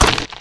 脚步－在石子地面-YS070521.wav
通用动作/01人物/01移动状态/04沙石地/脚步－在石子地面-YS070521.wav
• 声道 單聲道 (1ch)